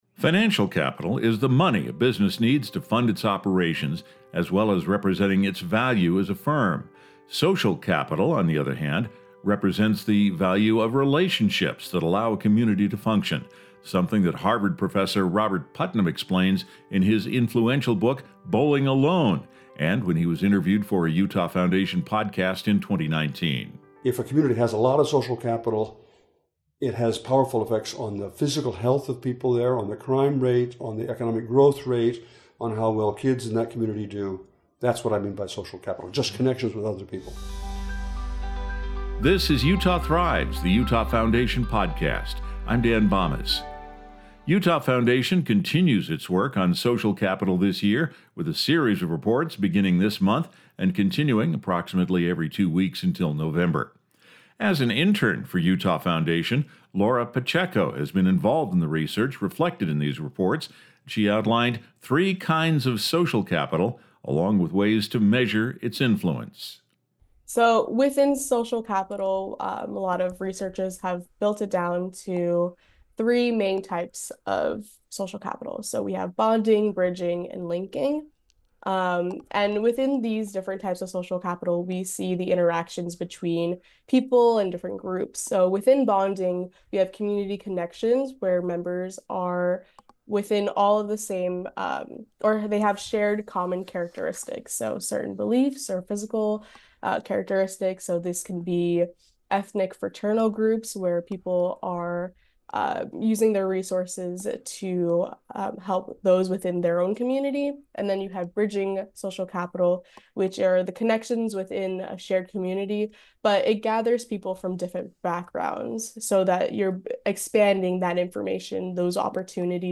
We revisit a clip of our interview with Robert Putnam in 2019